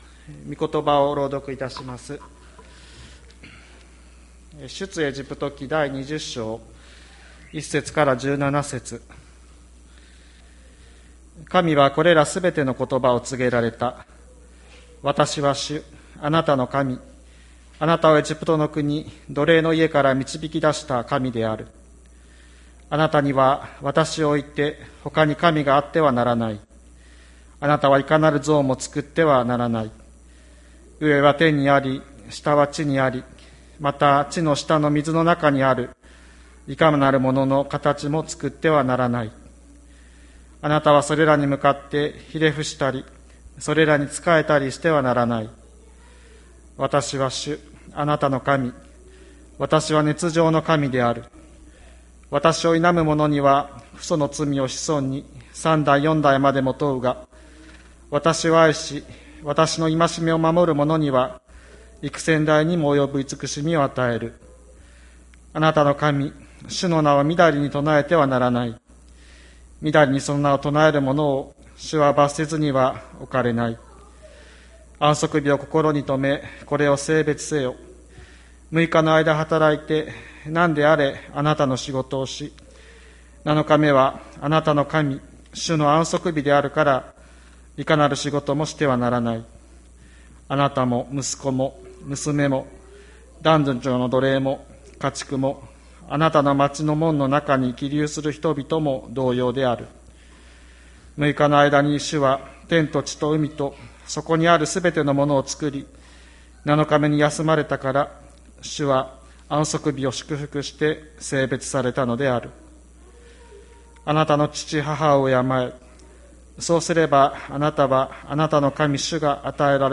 2021年07月25日朝の礼拝「神を神として生きる」吹田市千里山のキリスト教会
千里山教会 2021年07月25日の礼拝メッセージ。